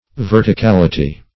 Verticality \Ver`ti*cal"i*ty\, n.